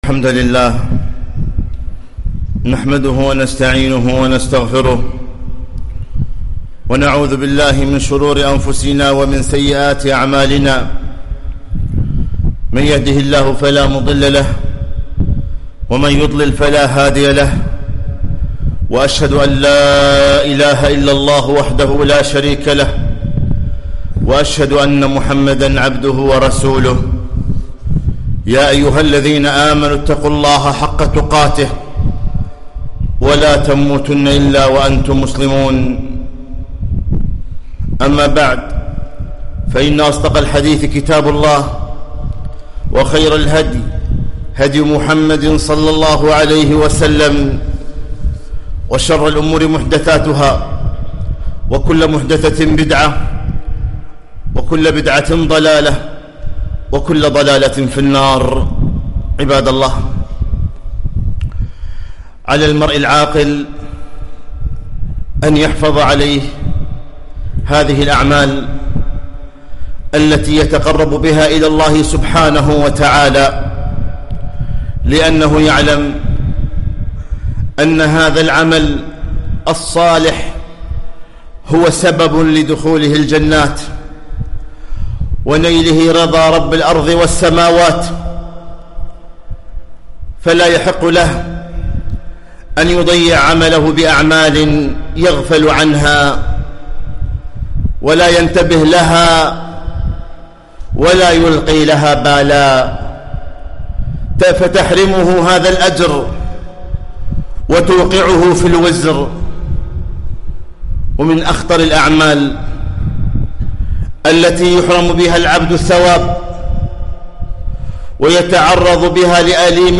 خطبة - لا تهدم حسناتك بلسانك